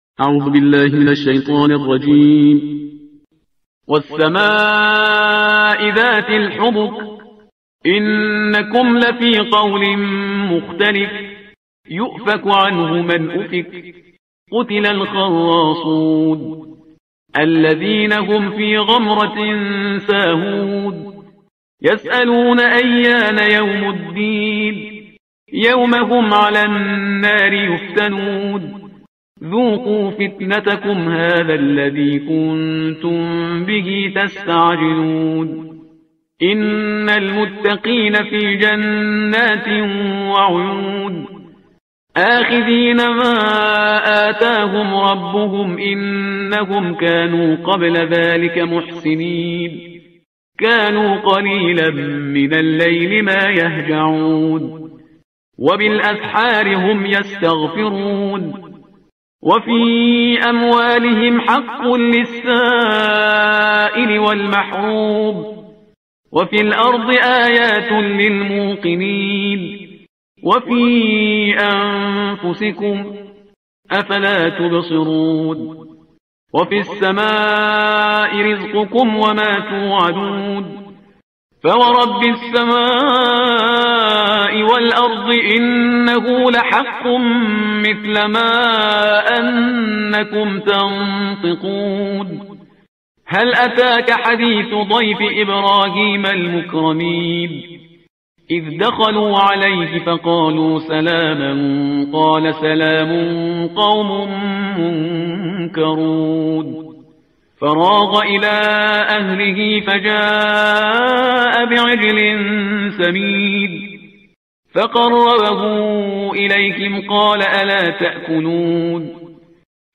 ترتیل صفحه 521 قرآن با صدای شهریار پرهیزگار
ترتیل صفحه 521 قرآن با صدای شهریار پرهیزگار ترتیل صفحه 521 قرآن – جزء بیست و ششم